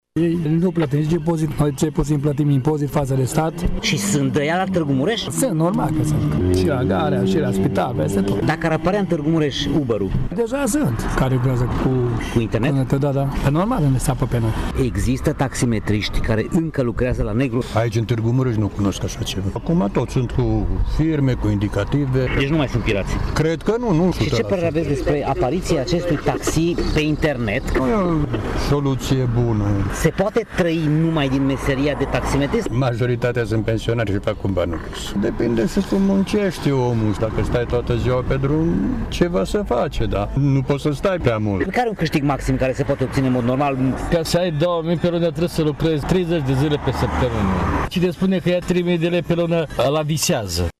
Cei care lucrează legal, prin dispecerat, dezaprobă modul de lucru al taximetriștilor solicitați online, care nu sunt autorizați și nu plătesc impozite către stat: